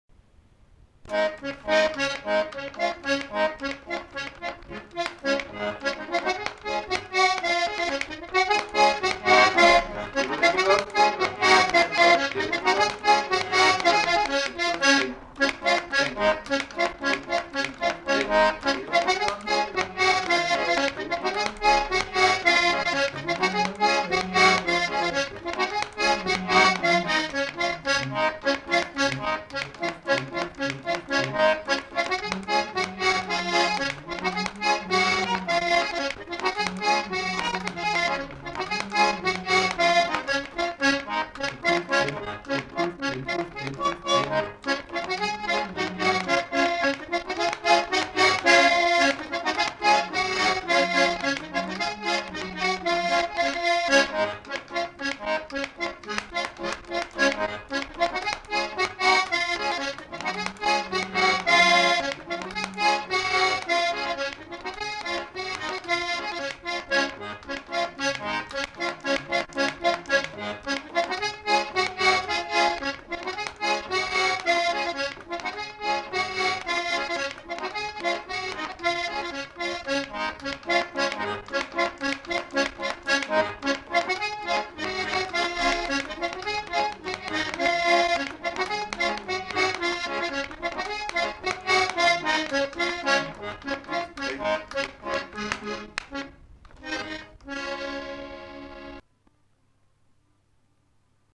Aire culturelle : Savès
Lieu : [sans lieu] ; Gers
Genre : morceau instrumental
Instrument de musique : accordéon diatonique